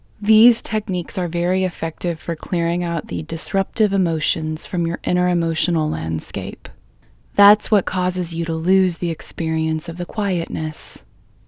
QUIETNESS Female English 22
Quietness-Female-22-1.mp3